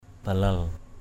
/ba-lal/ (cv.) bilal b{lL 1.